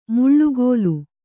♪ muḷḷugōlu